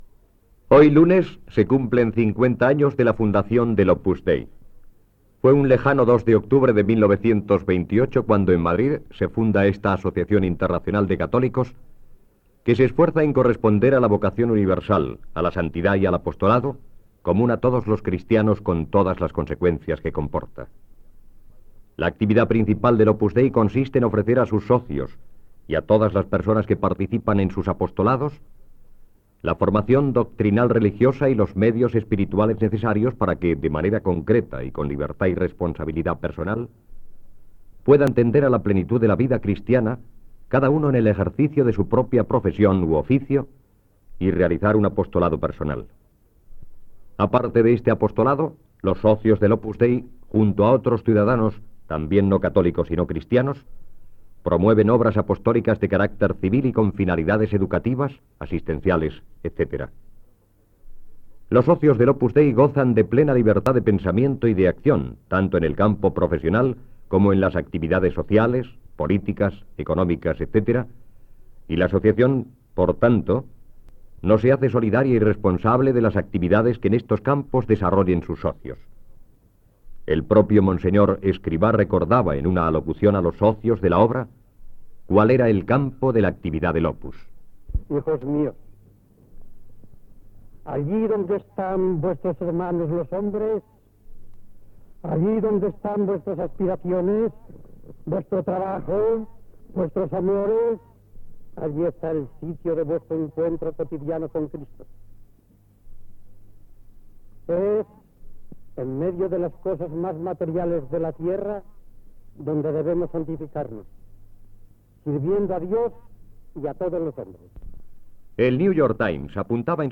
Cinquanta anys de la formació de l'Opus Dei. Activitat de l'organització religiosa, objectius i la figura del seu fundador José María Escrivá de Balaguer, amb declaracions seves.
Religió